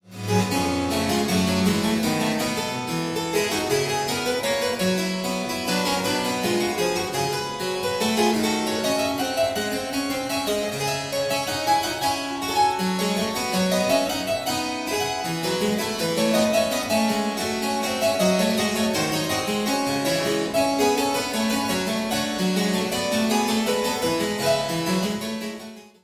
Bach, fuga XVI in g-klein uit het Wohltemperiertes Klavier, band 2, maat 22-31.